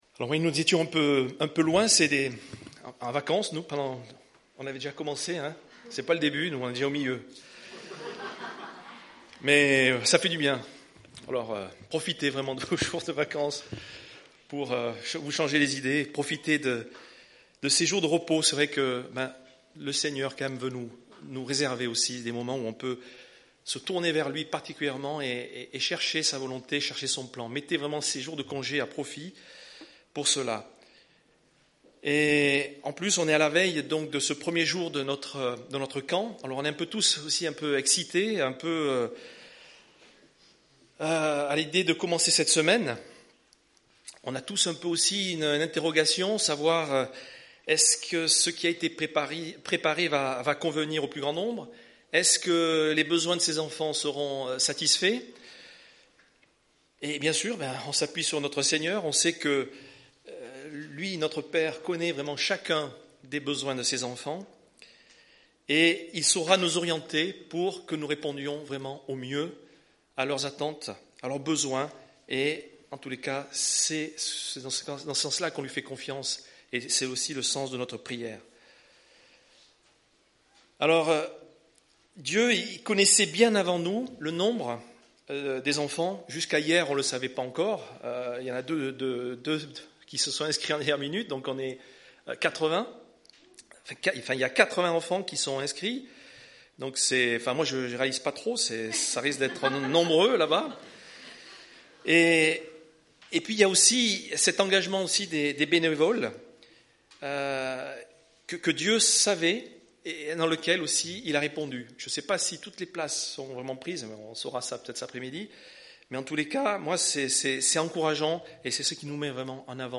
Culte du 14 juillet